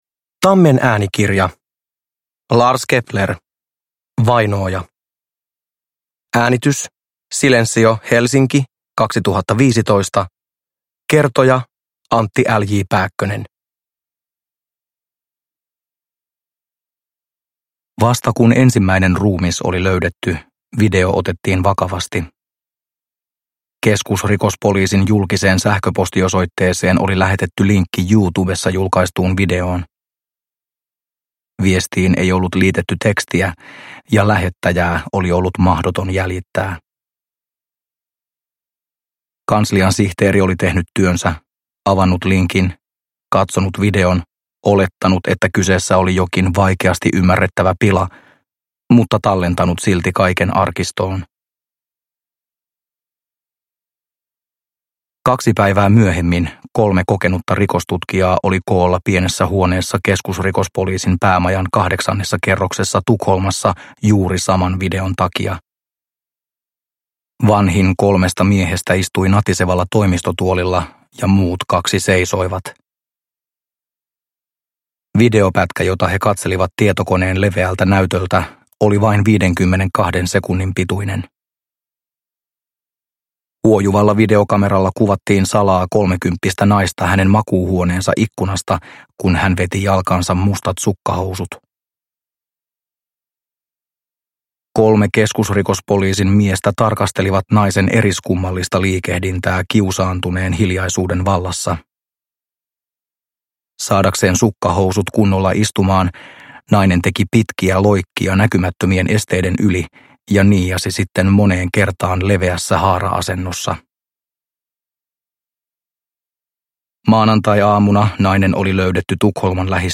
Vainooja – Ljudbok – Laddas ner